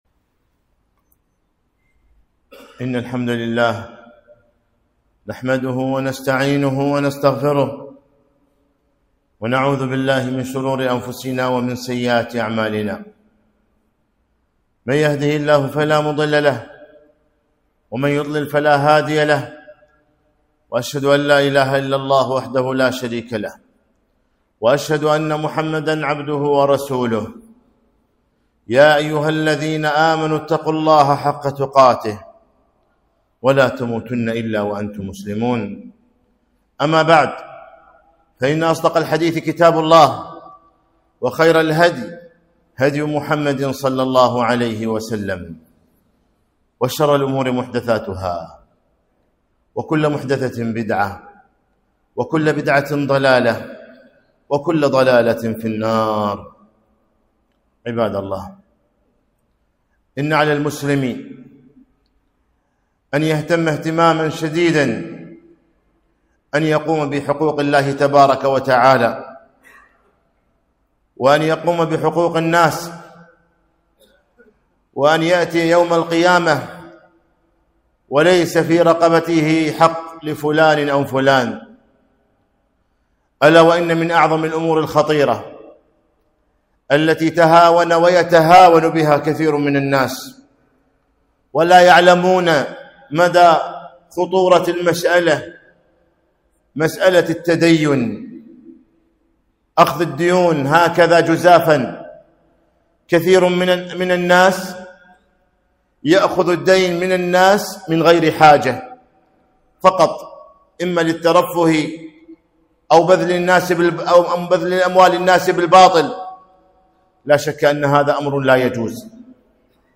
خطبة - لا تخيفوا أنفسكم من الدين